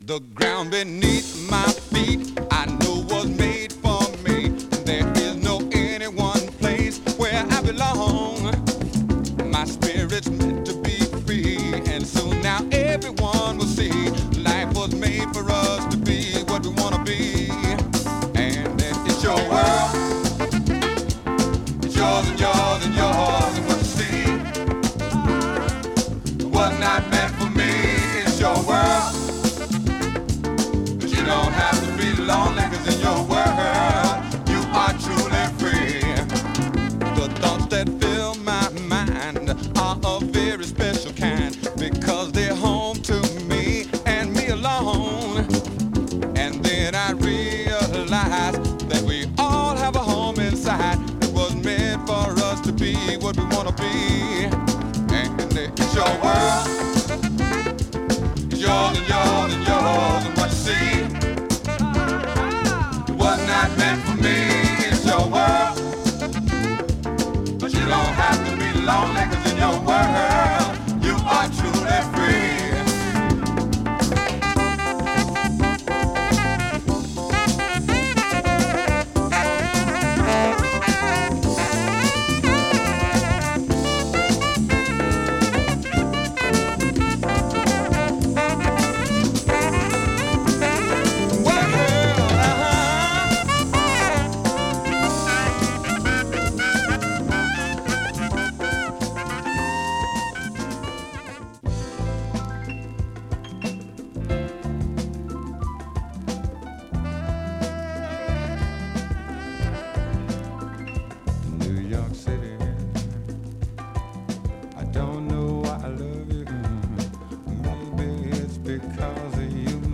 中古レコード LOFT〜RARE GROOVE CLASSIC !!
ライヴの生々しさで産まれ変わった名曲の数々を収録した屈指の名作。
JAZZ LP